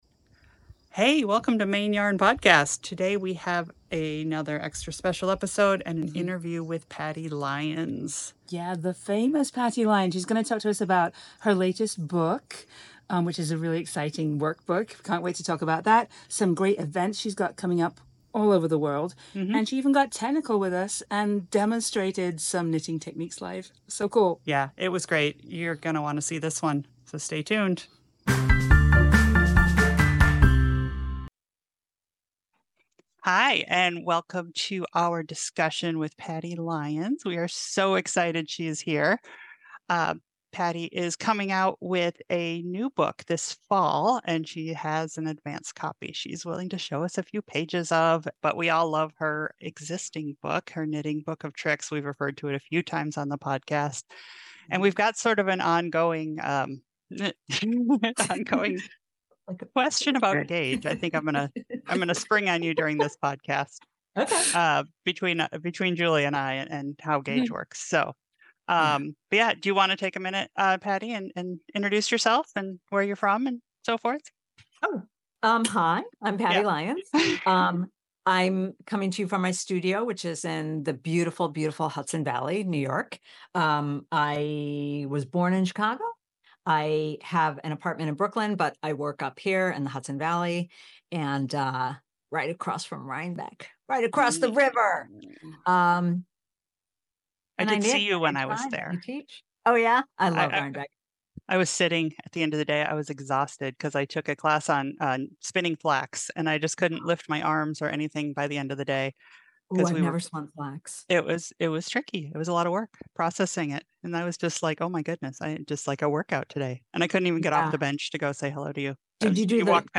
Maine Yarn Podcast An Interview